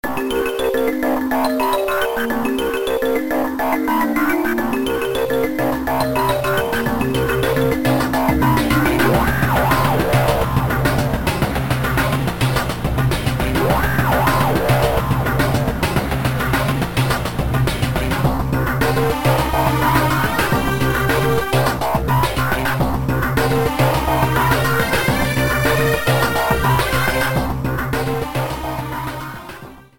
Second boss theme